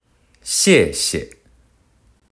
四聲である１つ目の「シェ」は語尾を下げるように発音し、二つ目の「シェ」は輕聲なので、短く切るように発音します。
▼「謝謝（ありがとう）」発音サンプル
※発音サンプルは、中国語ネイティブの台湾人の方にお願いしました。